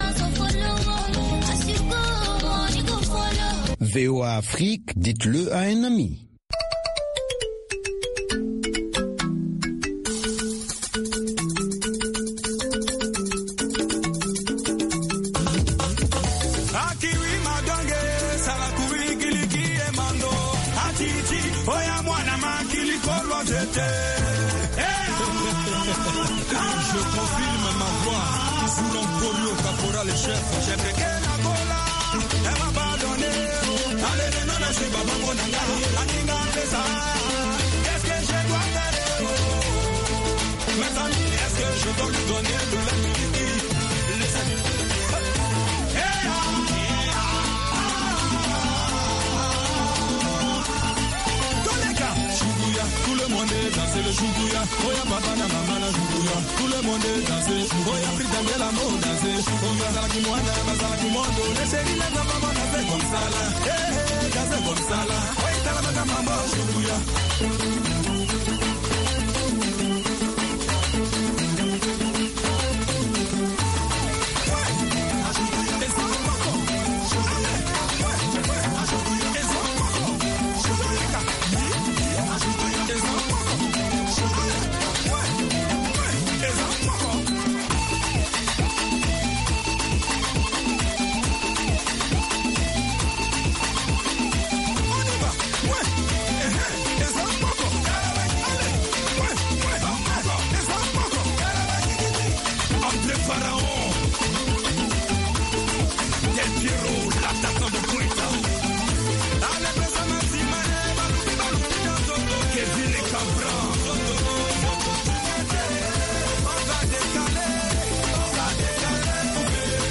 Bulletin d’information de 15 heures